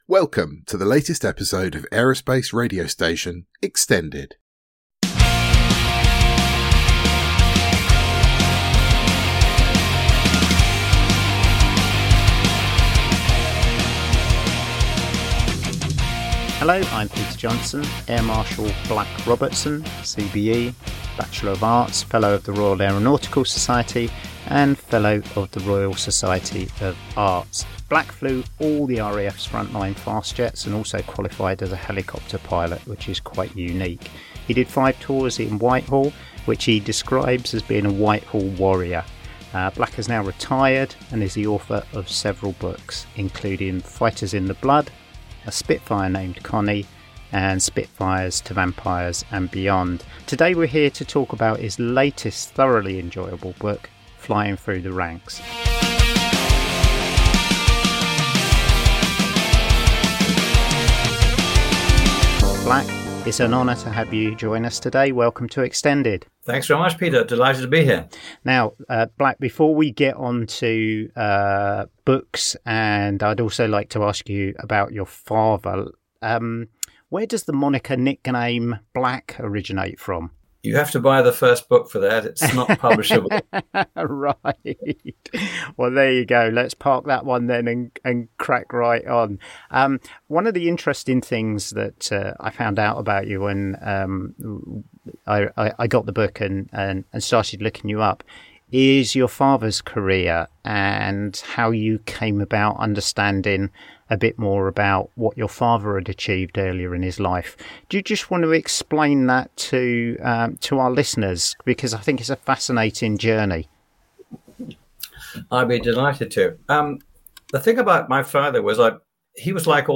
Today ‘Black’ joins us to talk about his latest book, ‘Flying through the Ranks: The Extraordinary Experiences of Airmen to Air Marshals from the Cold War to the Gulf’, his own flying experiences and how he discovered the real story behind his father’s World War 2 flying career.